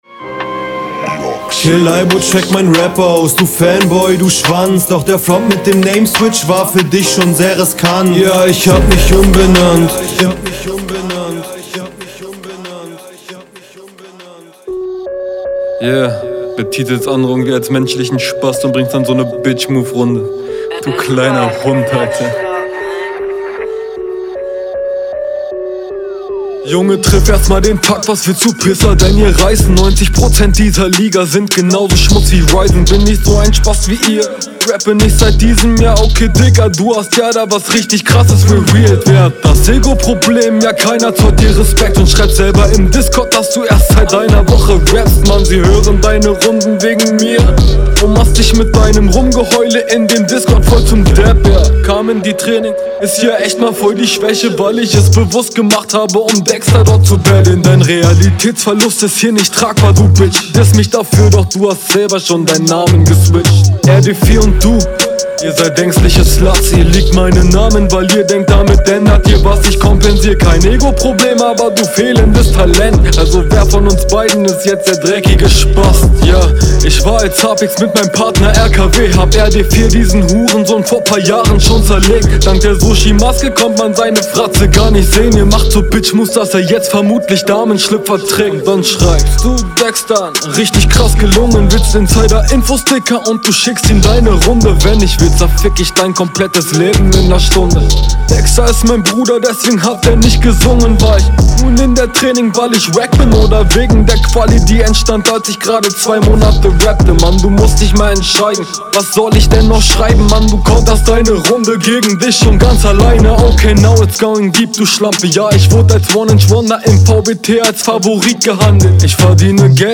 Auch schöne Runde man hört den Hass gut raus Delivery ist dazu auch nice finde …